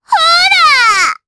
Pansirone-Vox_Attack2_jp.wav